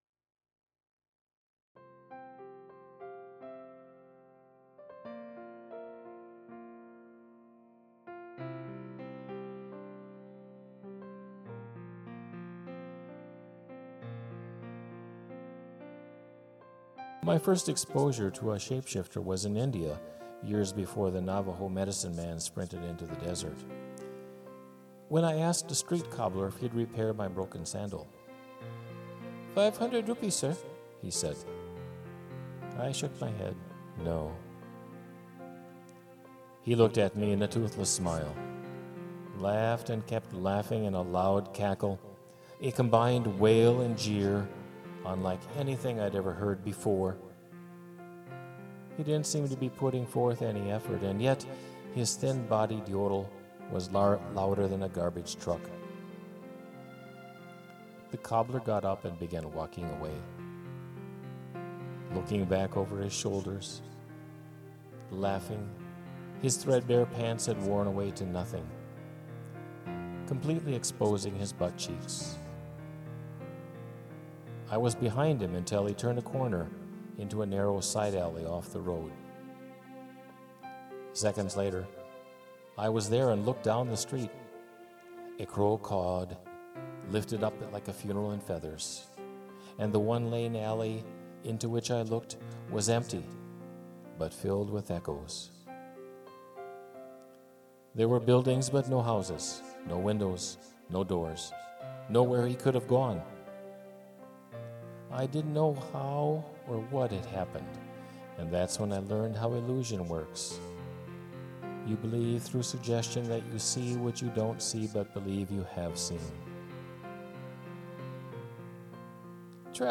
spoken word and music